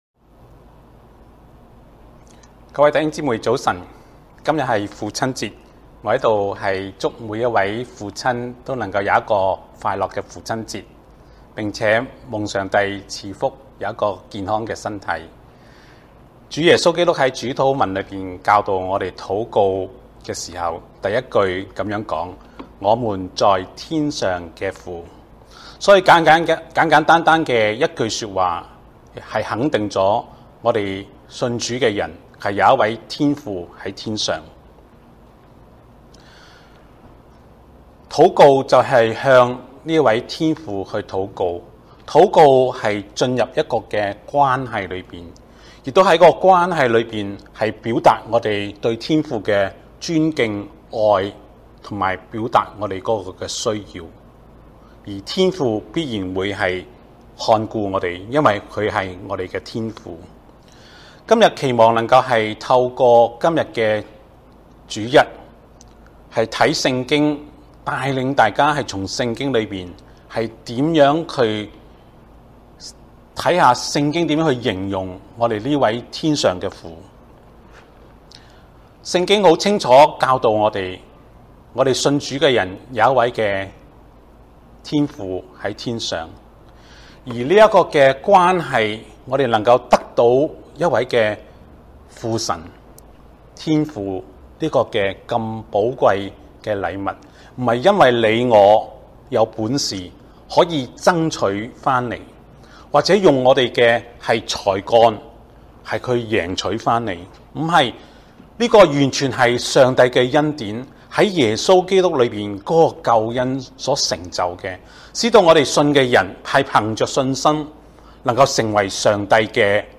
講道 - 粵語事工 | 溫哥華華人宣道會